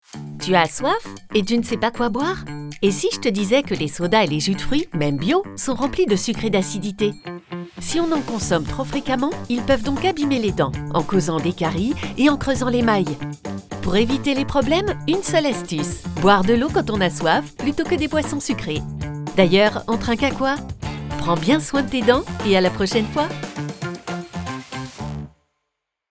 Voix pour une série de vidéos sur la santé bucco-dentaire destinés aux enfants. Voix de personnage. Ton enfantin, complice et enjoué.